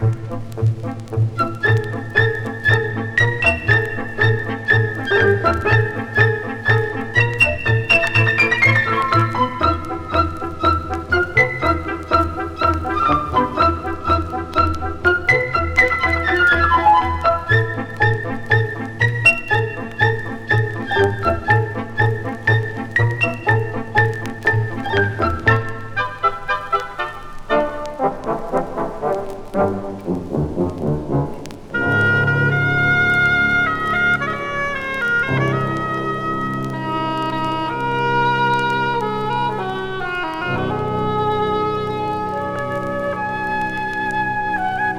Classical, Easy Listening, World　Japan　12inchレコード　33rpm　Mono